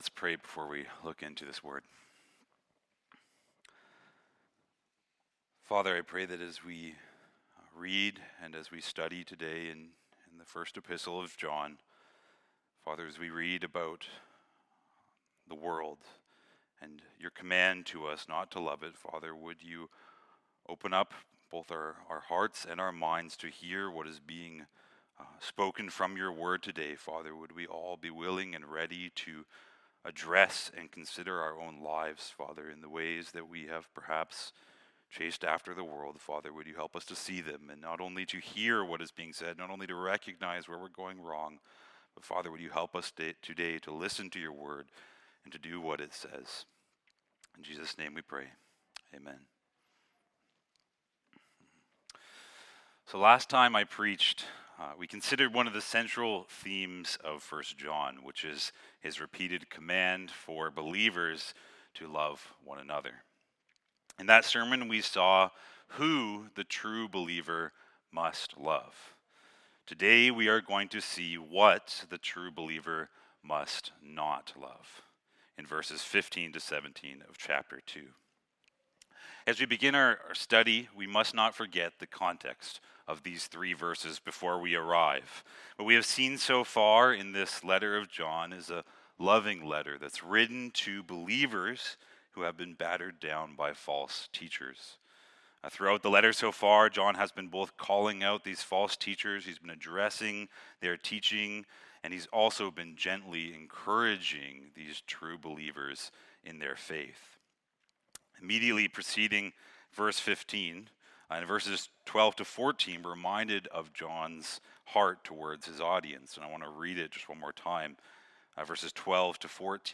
Sermons - Christ Community Church